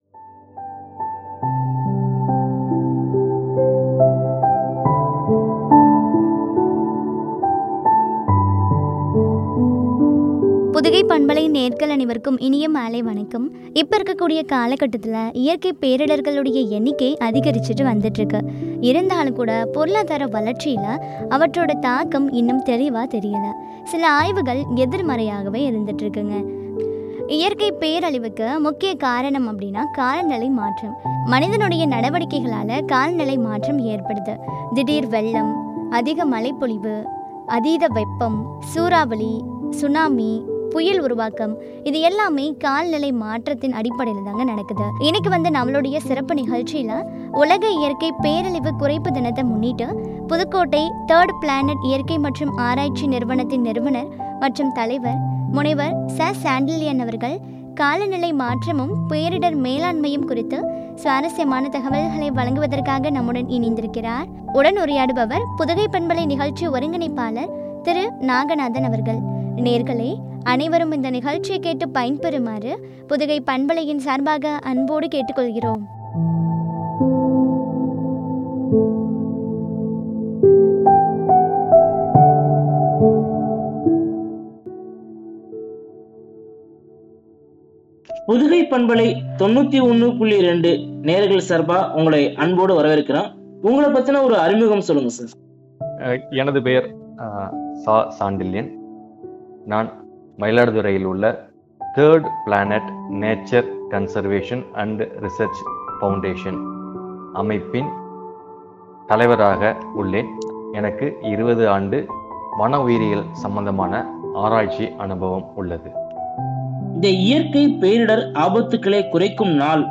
” காலநிலை மாற்றமும் பேரிடர் மேலாண்மையும் ” குறித்து வழங்கிய உரையாடல்.